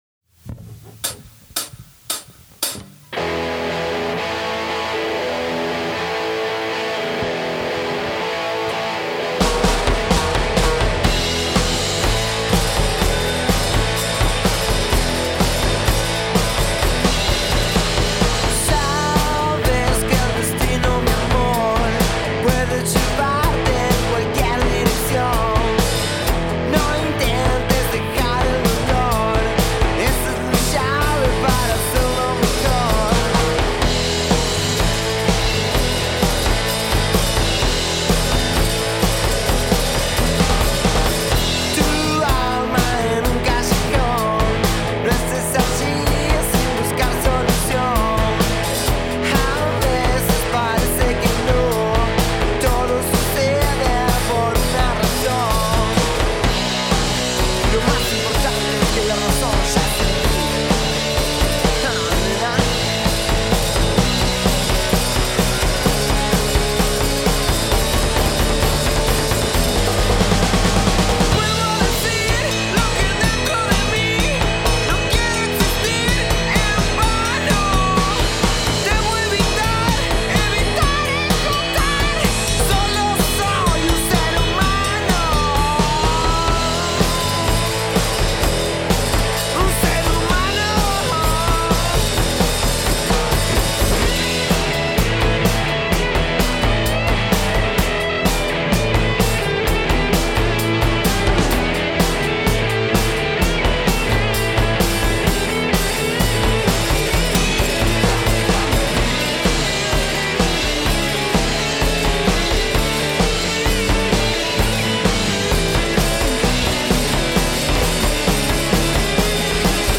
Band self recording, mixing and mastering
But, I think you can hear the triggered kick, at least.